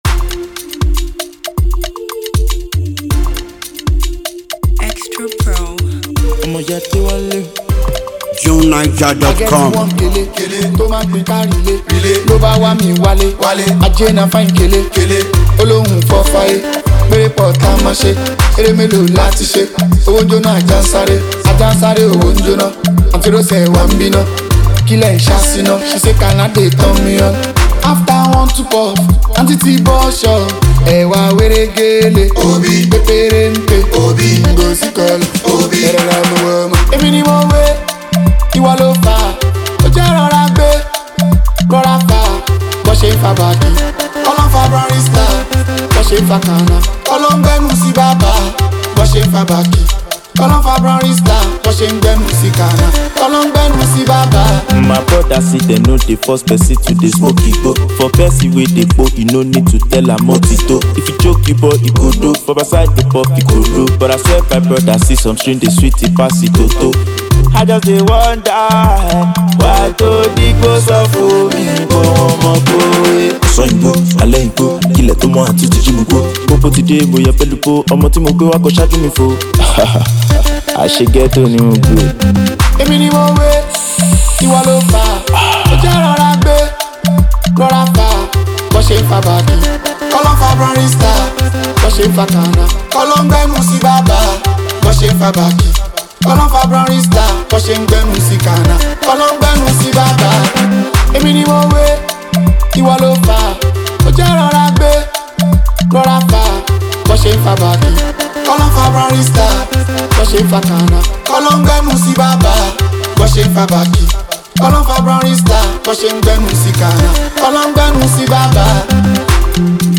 throbbing song